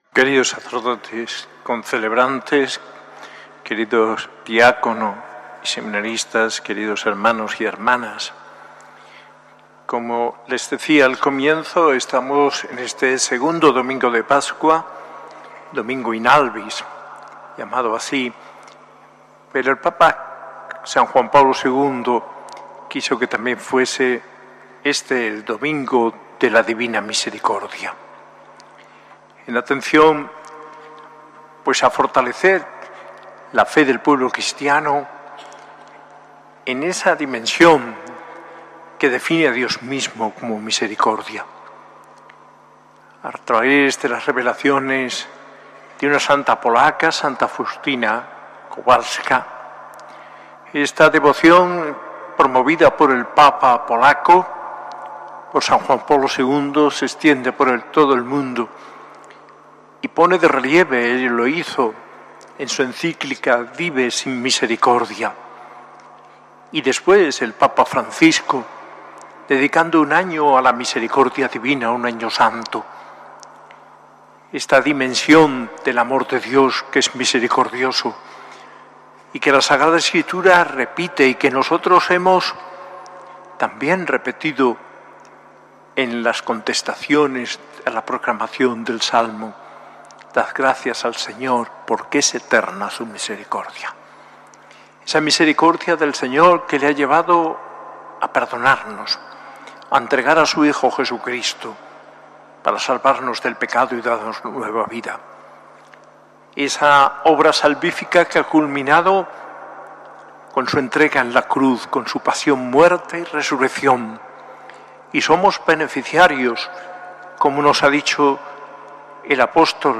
Arzobispo de Granada
S.A.I Catedral de Granada